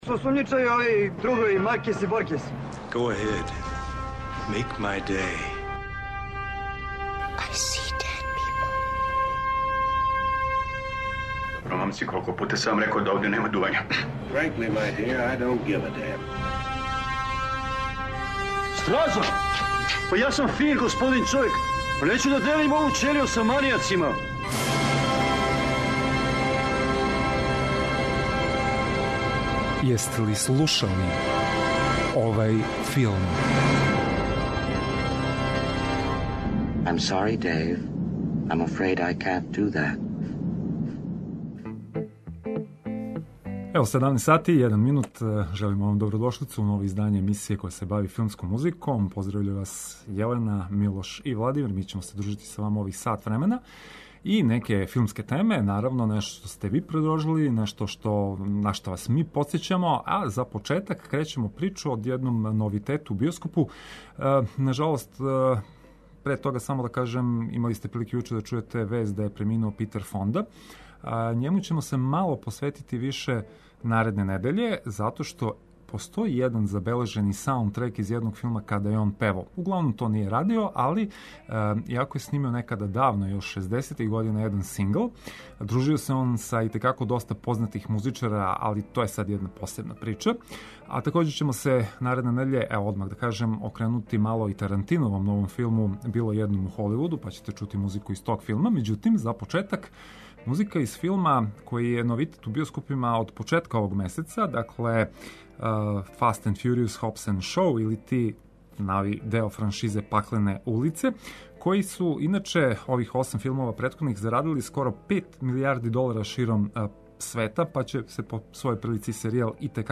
Филмска музика и филмске вести.